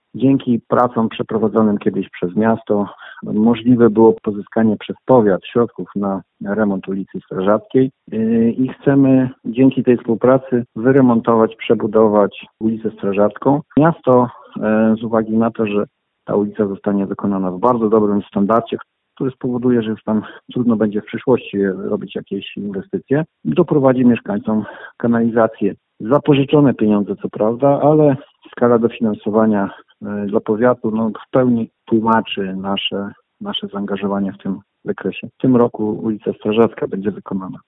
O szczegółach mówi burmistrz Arkadiusz Nowalski.